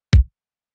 "Sounddesign" Bassdrum?
ich hatte mir gestern abend den spaß gemacht und hab auf die schnelle ne kick aus 30 layern gebastelt. eigentlich ist es nicht mehr nur ne kick.